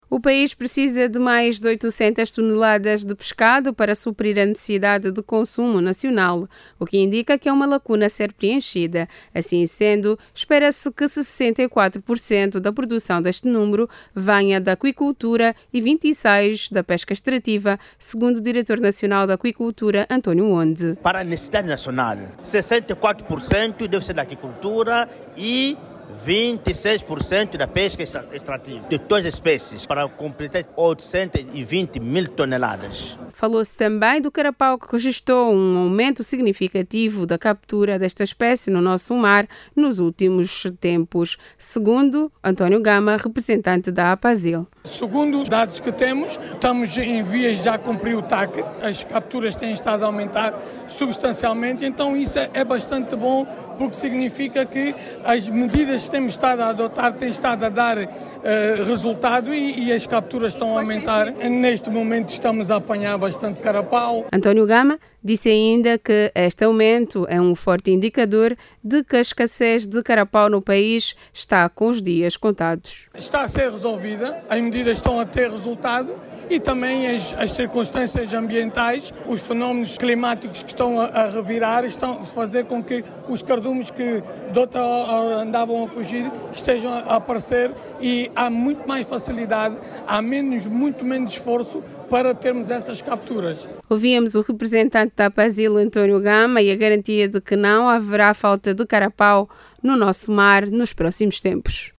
De acordo com a APASIL, apesar das necessidades ainda existentes, o país regista actualmente bons níveis de captura de pescado, com destaque para o carapau. Jornalista